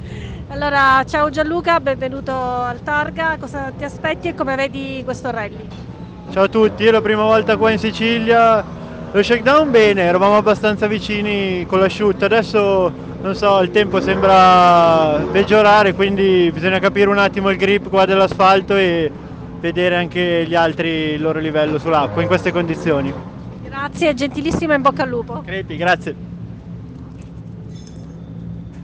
Interviste 106° Targa Florio
Interviste pre-gara GR Yaris Rally Cup